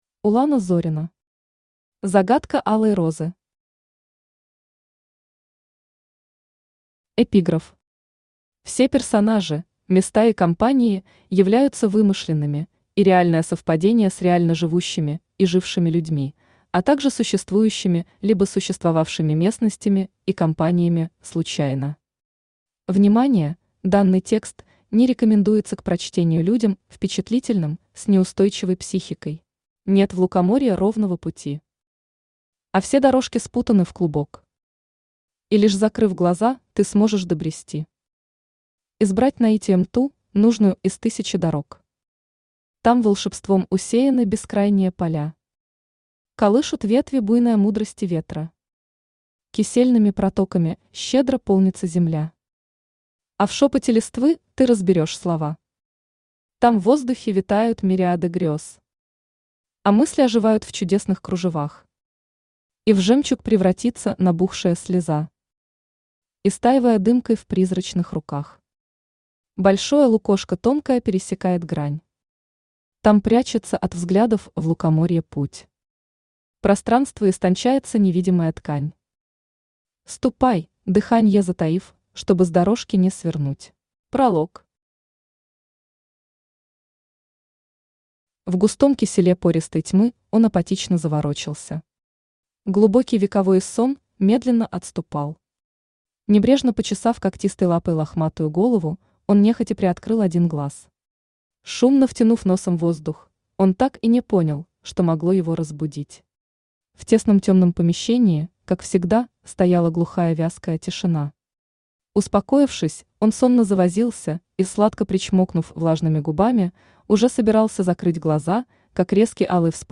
Аудиокнига Загадка алой розы | Библиотека аудиокниг
Aудиокнига Загадка алой розы Автор Улана Зорина Читает аудиокнигу Авточтец ЛитРес.